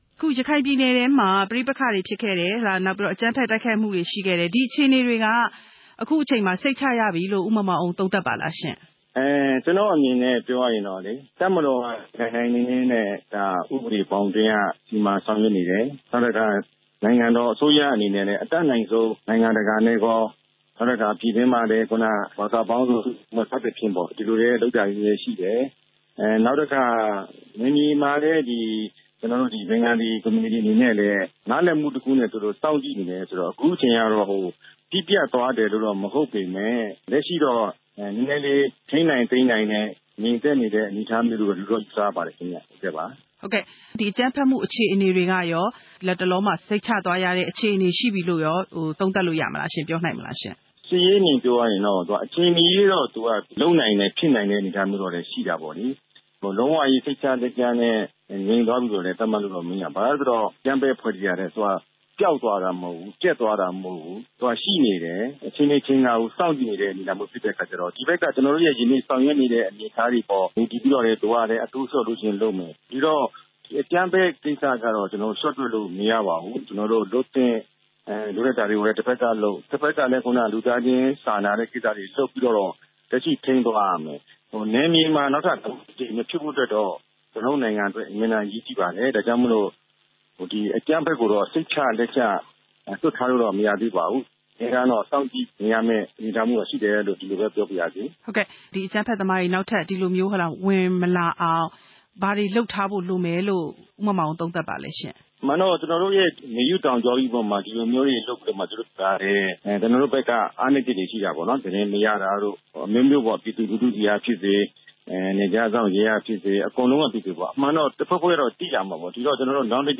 ရခိုင်ပြည်နယ်အရေး၊ ဝန်ကြီးချုပ်ဟောင်း ဦးမောင်မောင်အုန်းနဲ့ မေးမြန်းချက်